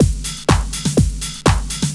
Rider Beat 3_123.wav